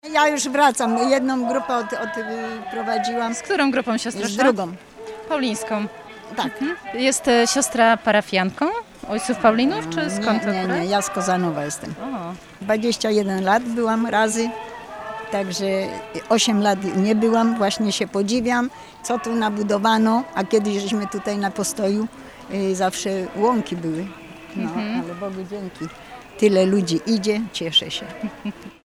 Udało nam się również na drodze spotkać siostrę, która jest pielgrzymem duchowym.